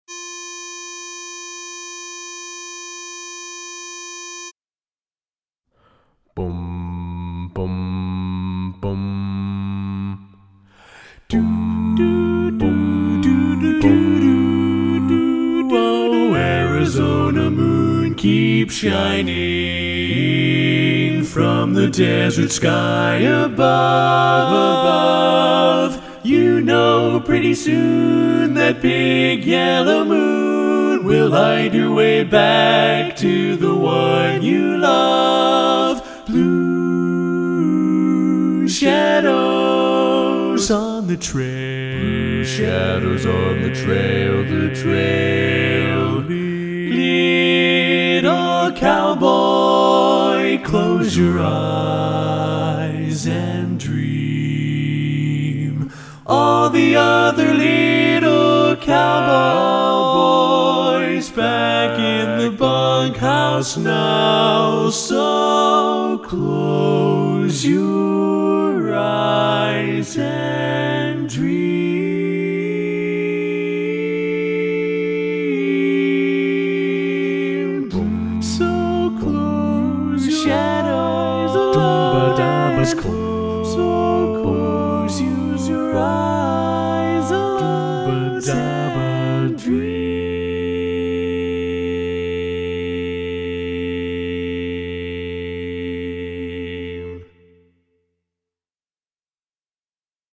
Minus Bari   Bass Pred